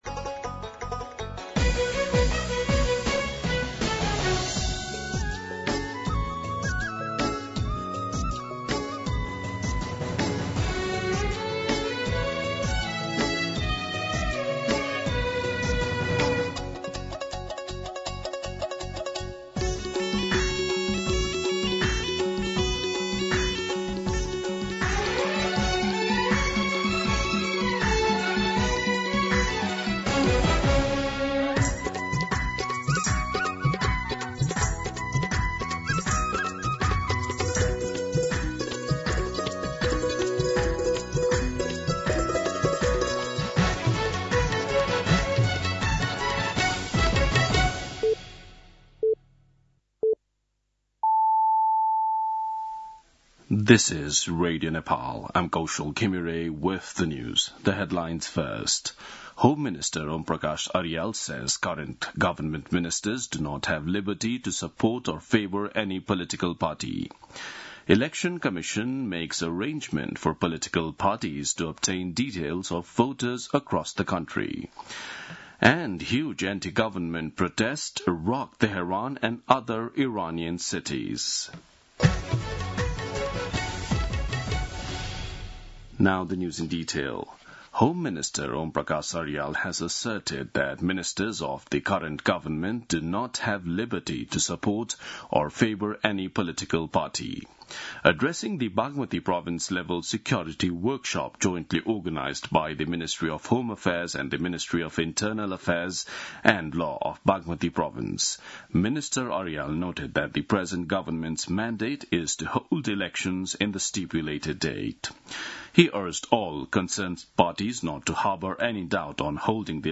दिउँसो २ बजेको अङ्ग्रेजी समाचार : २५ पुष , २०८२